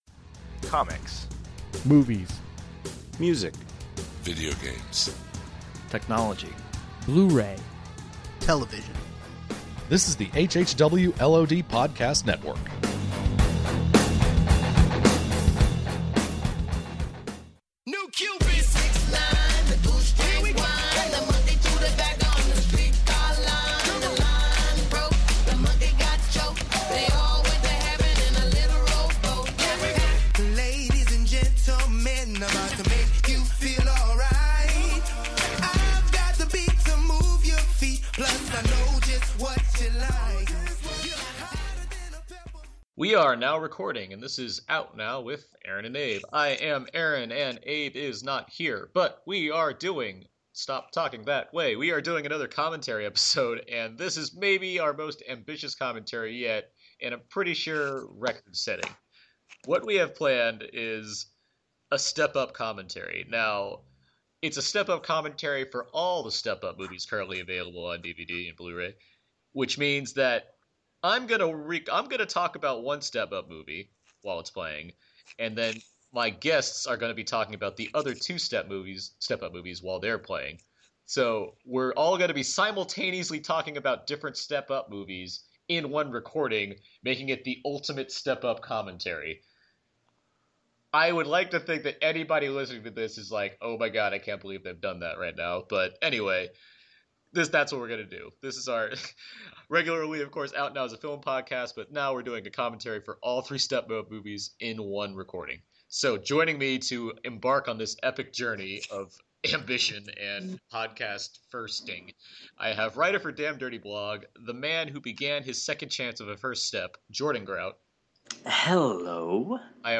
Out Now Bonus - Audio Commentary for All Step Up Movies In One
The most important thing to note about this episode is that four of us watched all three Step Up movies simultaneously and recorded a commentary that can go along side any of them. So basically anyone can choose whichever Step Up movie and use this commentary to help supplement it.